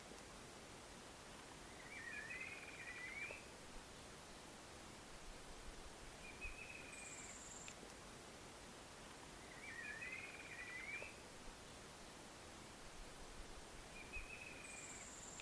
Rufous-margined Antwren (Herpsilochmus rufimarginatus)
Province / Department: Misiones
Location or protected area: Bio Reserva Karadya
Condition: Wild
Certainty: Observed, Recorded vocal